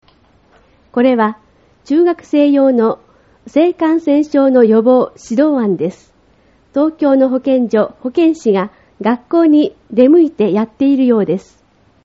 音声による説明 　これは、中学生用の「性感染症の予防」指導案です。